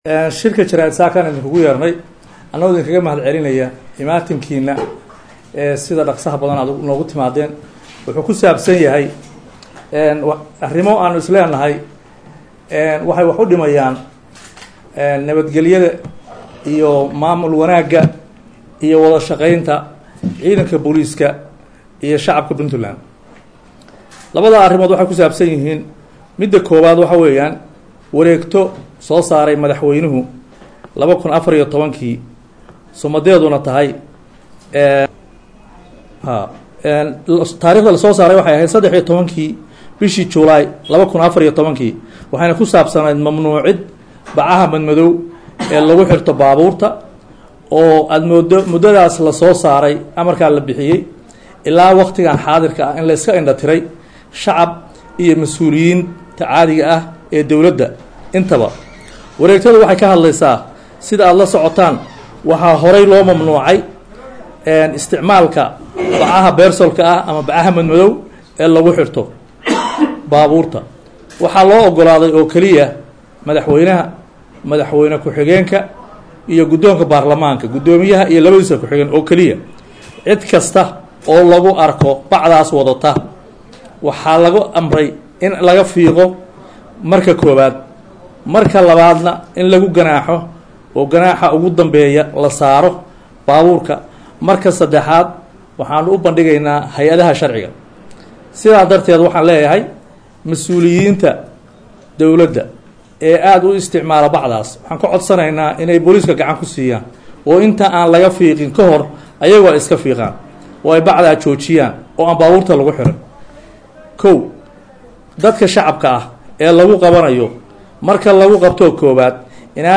19 dec 2016 (Puntlandes) Taliyaha Ciidamada Booliska Puntland Jen.C/qaadir Shire Faarax (Erag) oo Maanta Shir Jaraa’id ku qabtey Taliska Ciidamada Booliska Puntland ee Garowe ayaa Mamnuucay isticmaalka Bacaha Madow ee Muraayadaha Baabuurta  lagu dhajiyo.